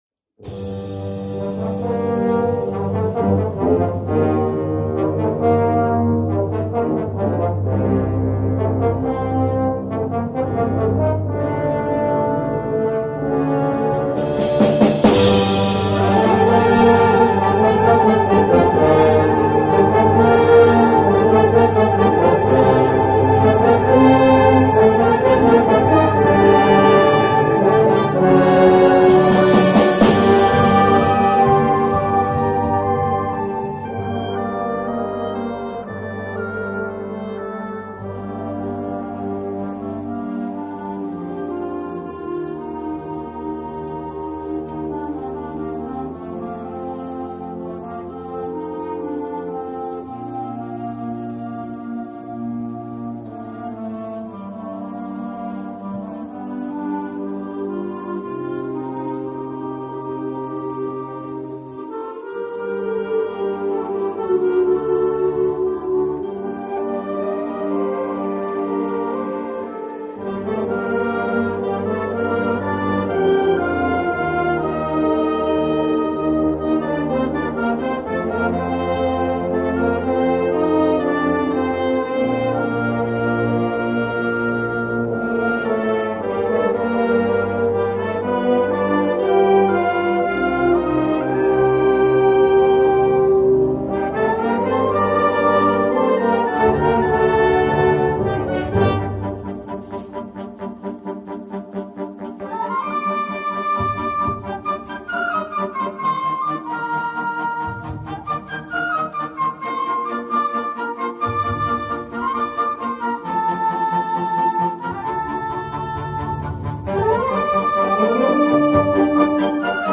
Original compositions